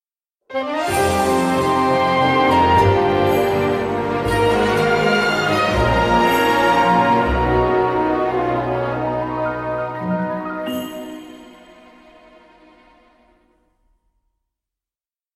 Download Sheikah Tower Activated sound effect for free.